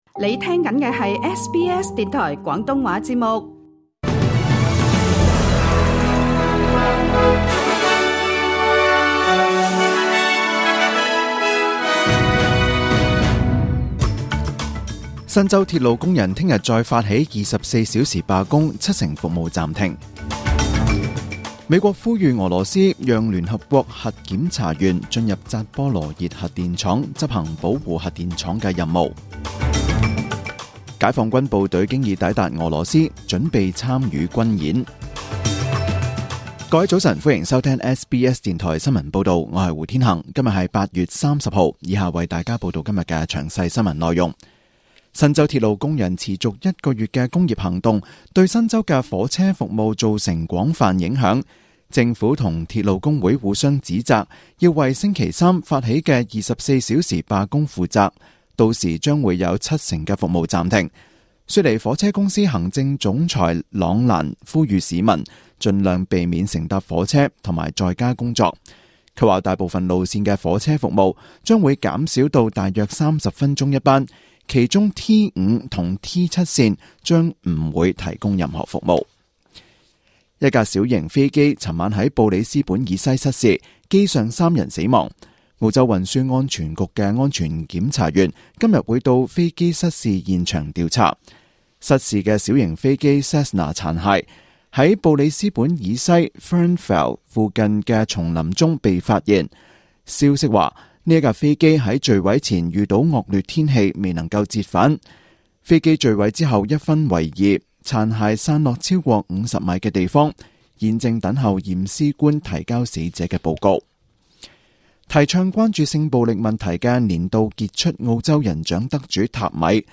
SBS 中文新聞（8月30日）